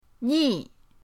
ni4.mp3